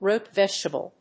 アクセントróot vègetable